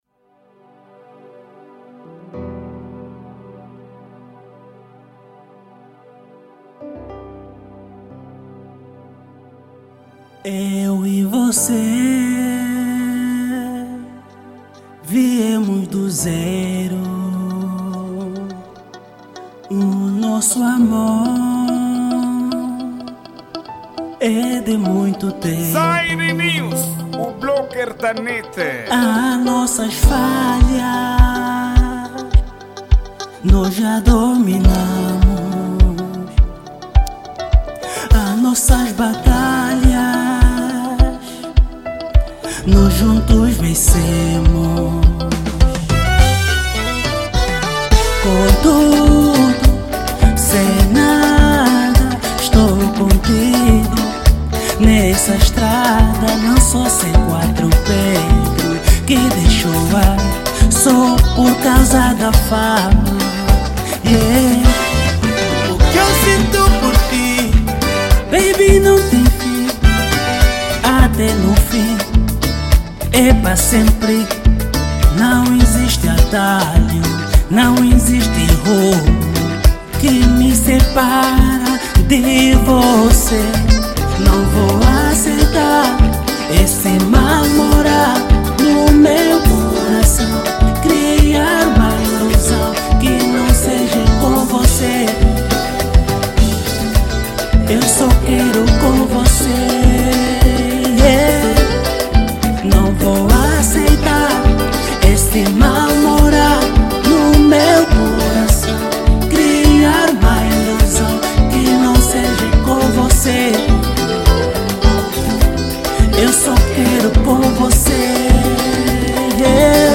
Gênero:Kizomba